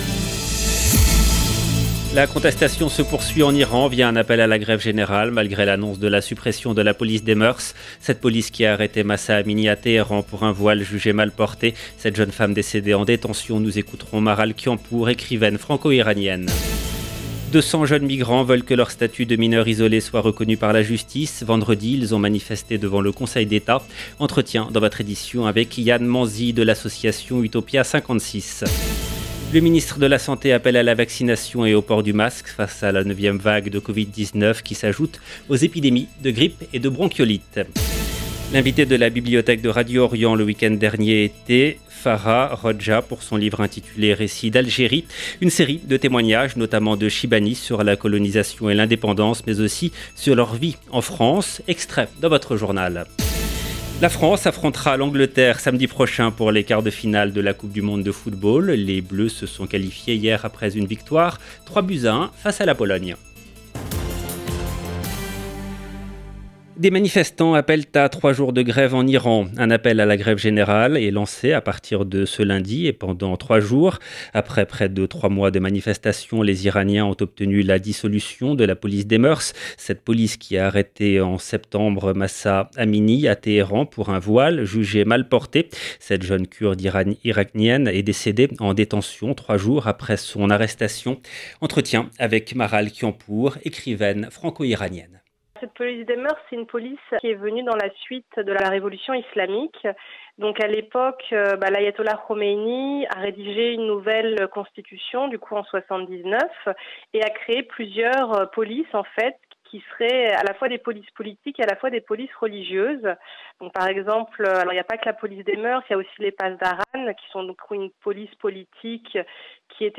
LE JOURNAL DE MIDI EN LANGUE FRANCAISE DU 5/12/22